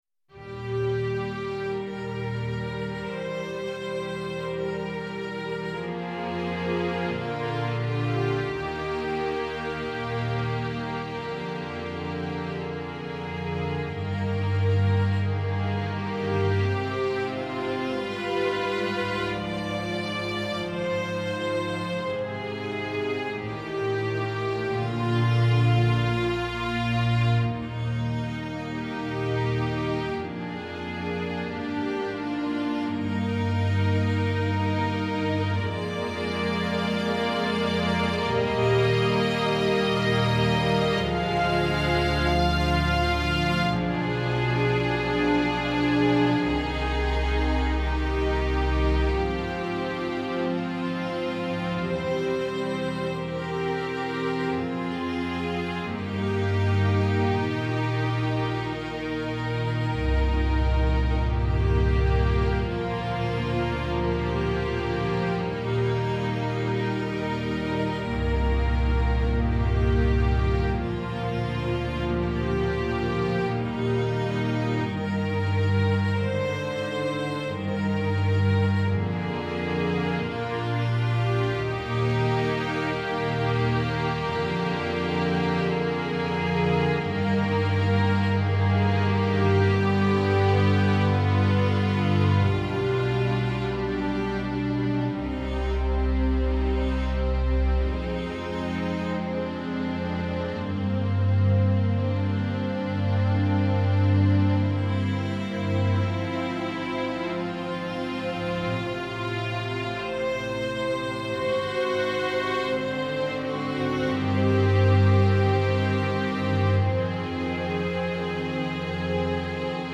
Beethoven-String-Quartet-No13-5th-Cavatina.mp3